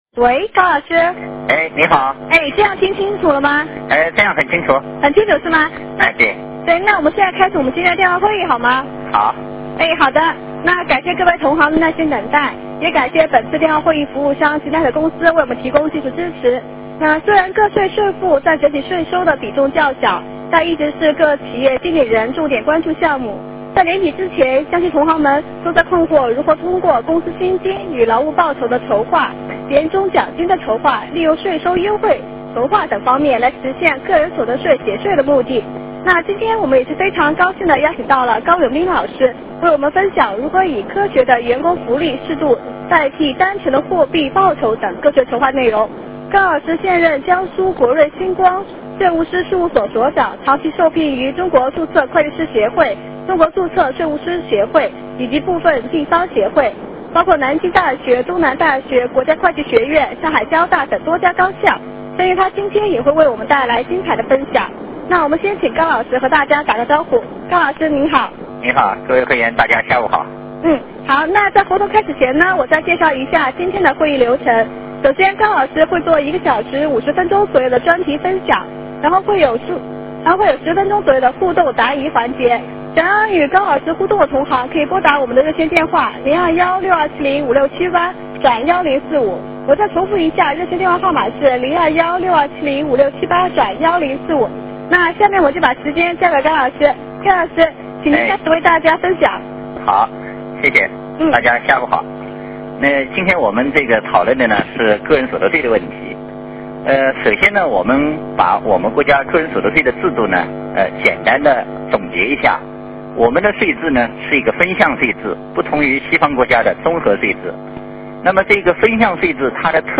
电话会议
互动问答